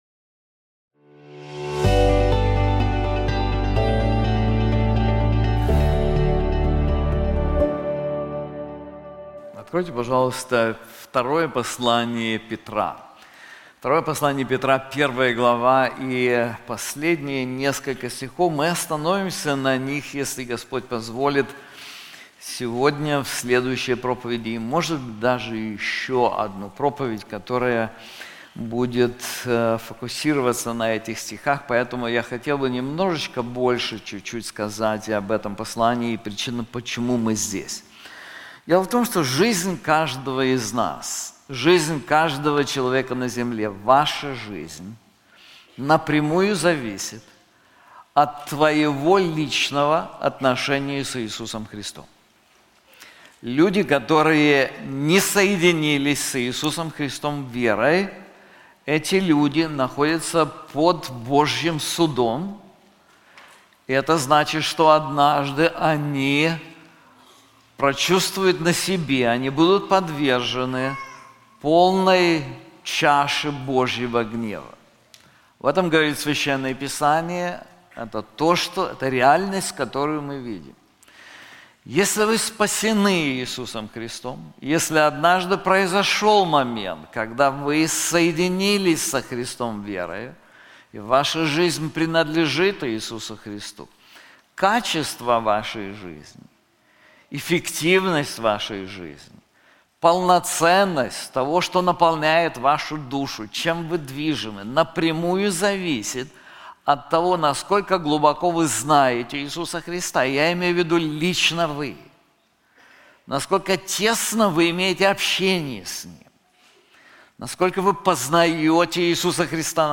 This sermon is also available in English:Scripture and Its Nature • 2 Peter 1:16-21